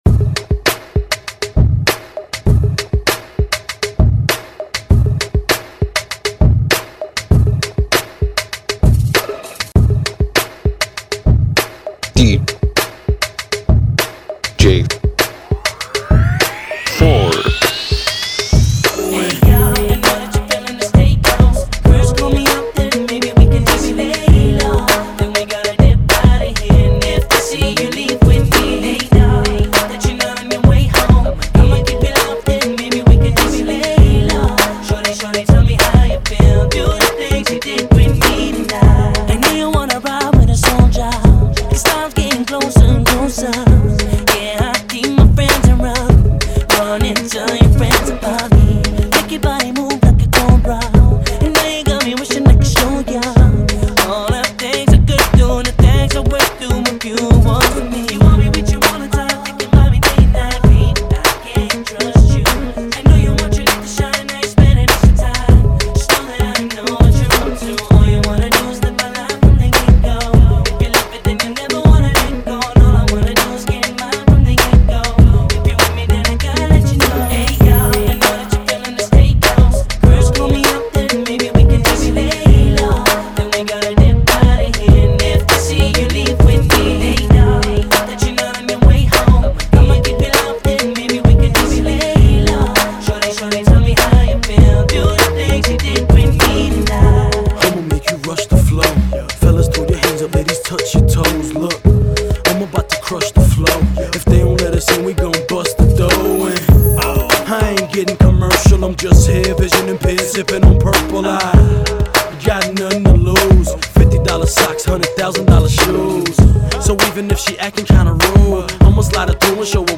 Funky